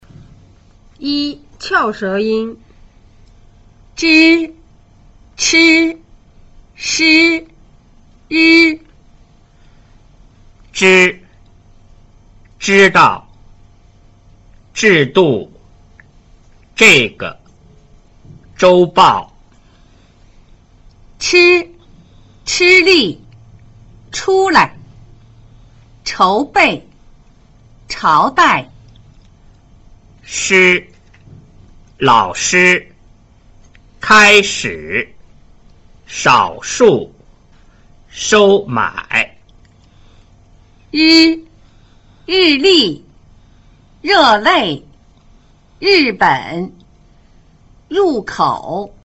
1 翹舌音  :   zh   ch   sh   r
在發這組音時，舌尖翹起頂到硬齶部位。請注意發 sh 時，喉頭放鬆不顫動；但是發 r 時，聲帶稍微縮緊，喉頭會產生顫動。